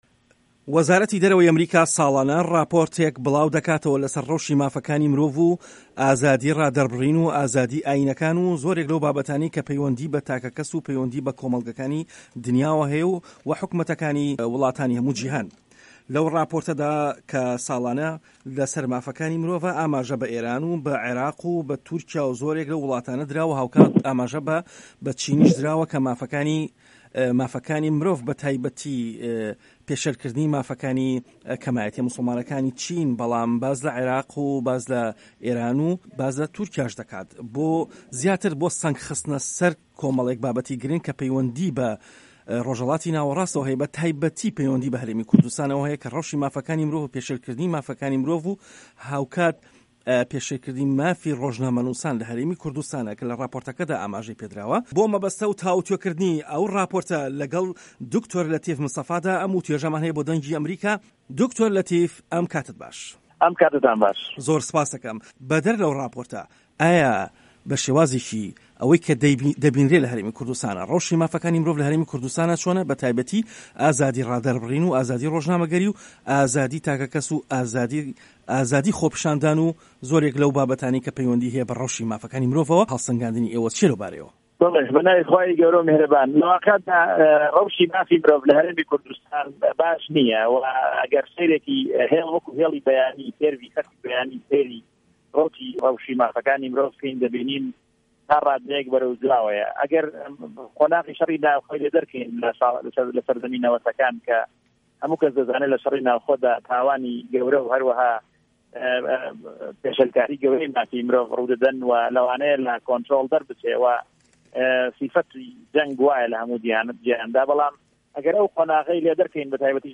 وتووێژ لەگەڵ دکتۆر لەتیف مستەفا